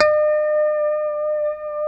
E5 PICKHRM1A.wav